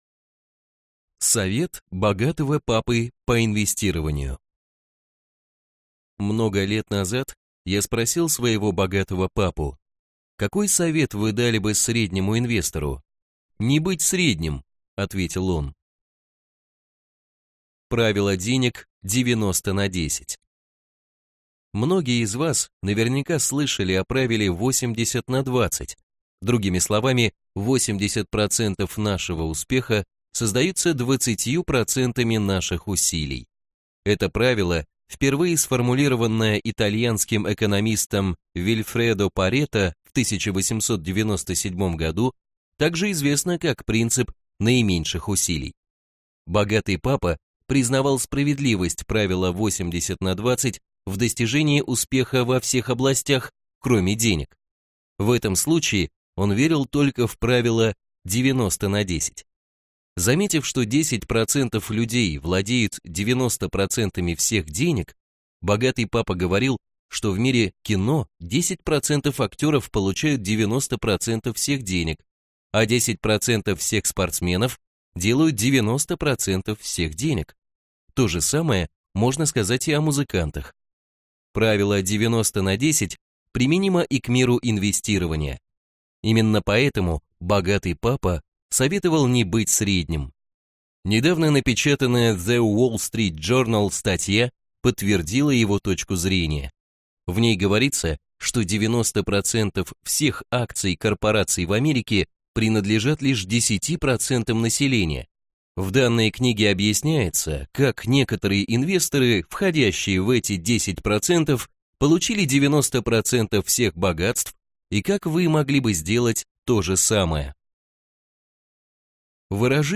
Аудиокнига Руководство богатого папы по инвестированию (обновленное издание) | Библиотека аудиокниг